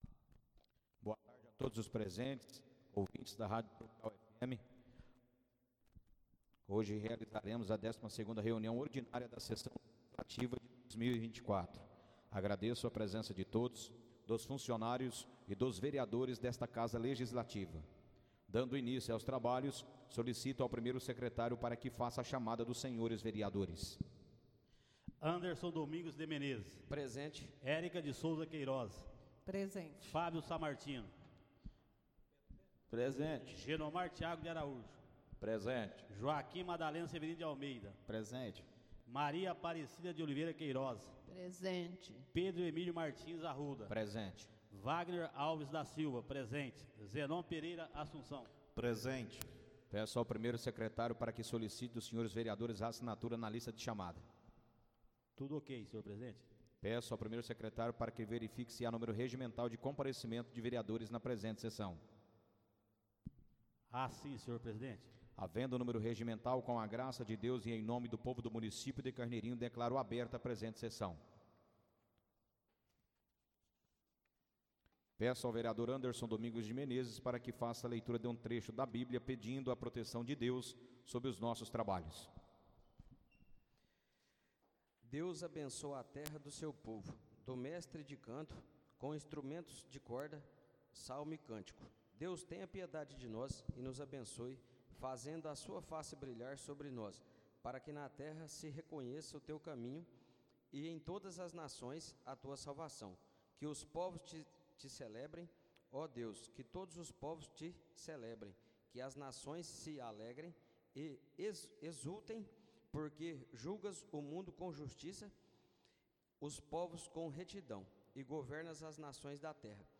Áudio da 12.ª reunião ordinária de 2024, realizada no dia 5 de de 2024, na sala de sessões da Câmara Municipal de Carneirinho, Estado de Minas Gerais.